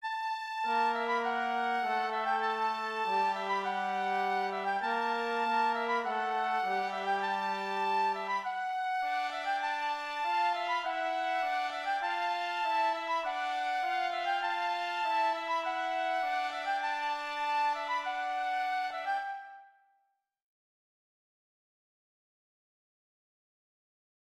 La montée vers le chiffre 3 va permettre de mener le thème B des ténèbres vers la lumière, de nouveau joué par les cuivres. Voici donc le motif obstiné en superposition avec ce thème B :